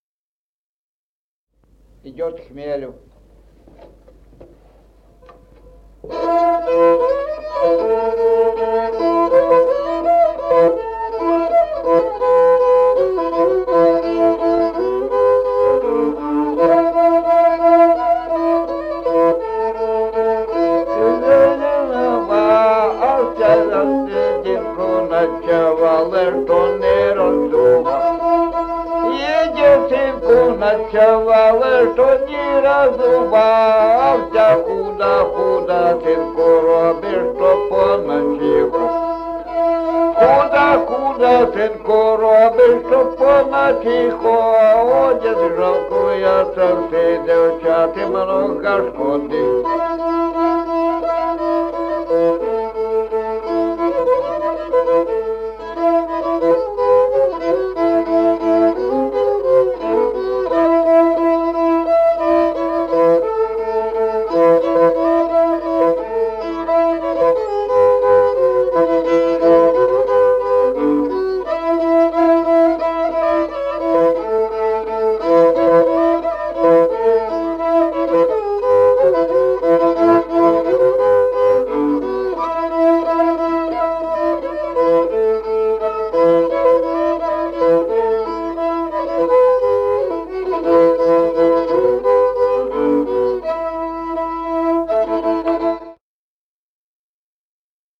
Музыкальный фольклор села Мишковка «Хмелю», репертуар скрипача.